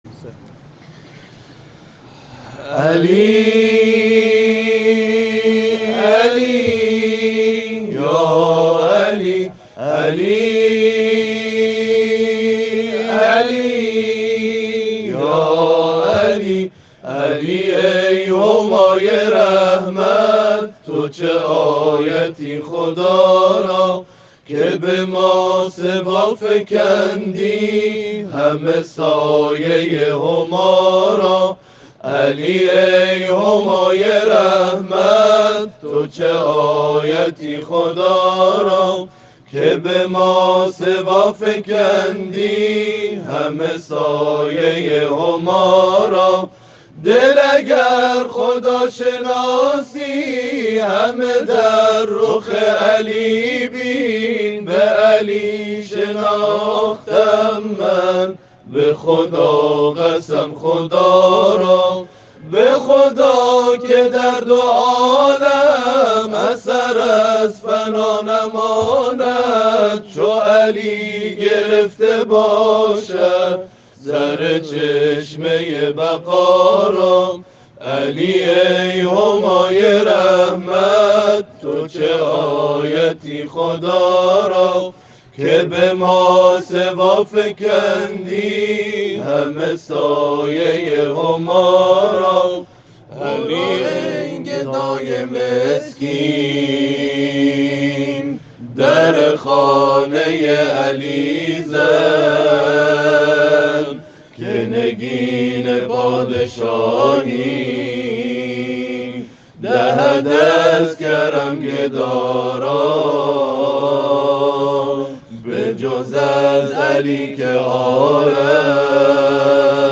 مراسم جشن عید سعیدغدیرخم با حضور گروهی از کاروان قرآنی اعزامی به حج تمتع(کاروان نور)، شنبه، 25 تیرماه در نمازخانه بیمارستان مرکز پزشکی حج و زیارت جمعیت هلال احمر در مکه مکرمه برگزار شد.
اجرای گروه تواشیح مصباح‌الهدی